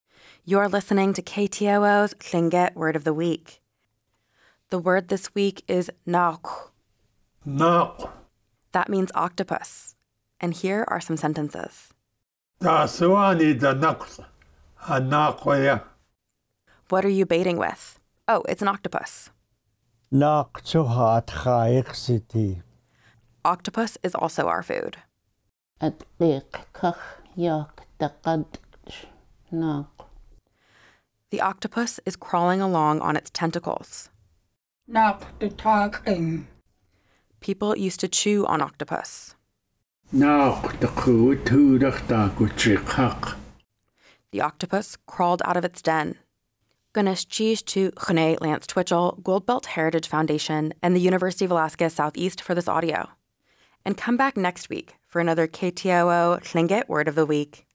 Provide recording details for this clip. You can hear each installment of Lingít Word of the Week on the radio throughout the week.